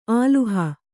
♪ āluha